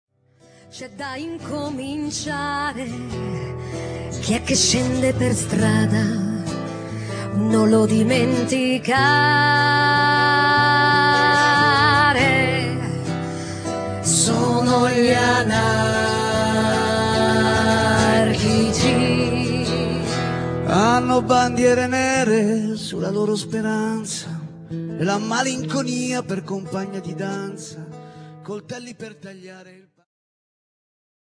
ジャンル Progressive
アコースティック
ローマ出身のアコースティック楽器と女性ヴォーカルによるグループ。